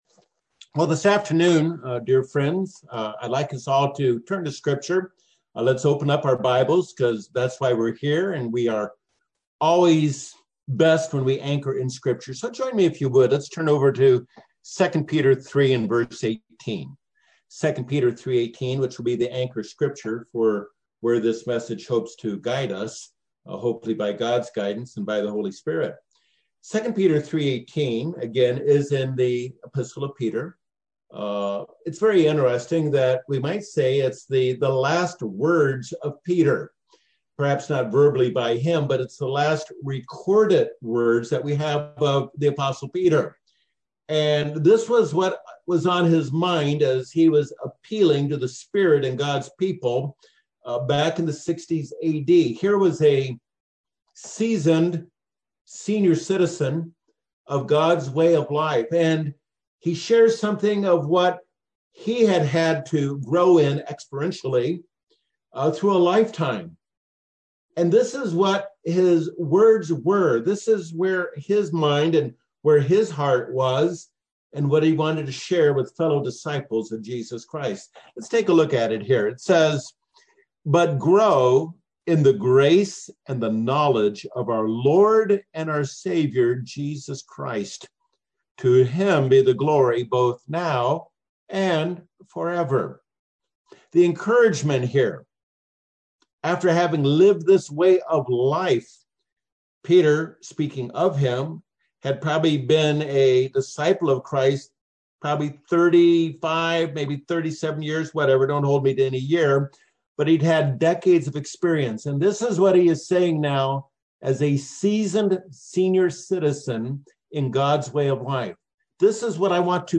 This message founded on 2 Peter 3:18 utilizes the example of Cornelius and Peter, two kingdom bringers, as to how to grow in grace and knowledge as God interrupts our lives to perform a "new thing" within His ageless purposes.